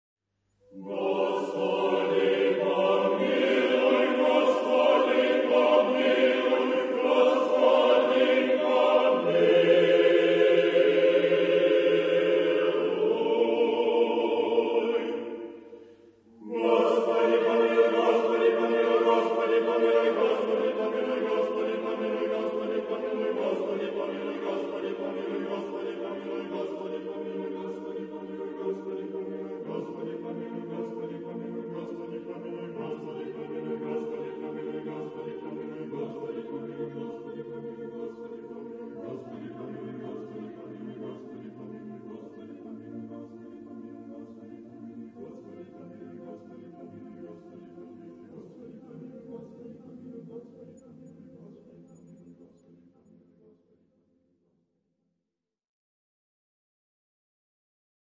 SATB (4 voices mixed).
Sacred. Romantic. Orthodox song.
Tonality: B flat major ; C minor